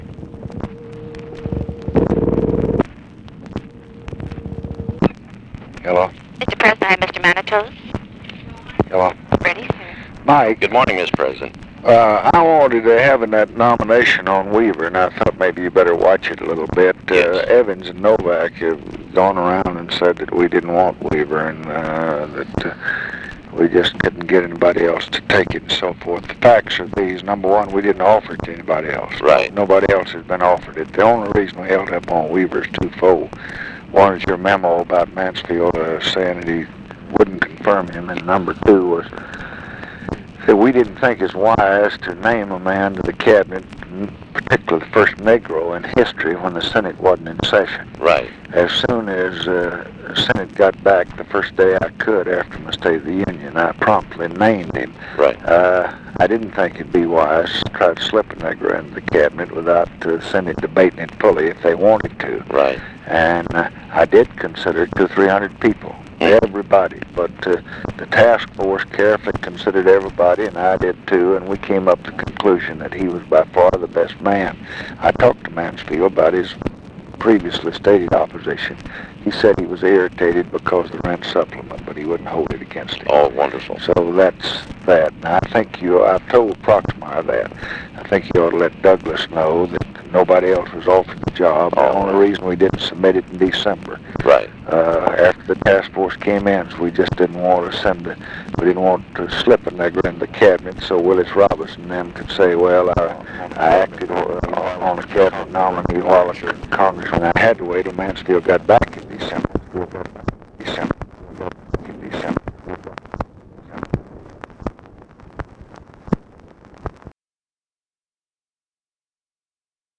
Conversation with MIKE MANATOS, January 17, 1966
Secret White House Tapes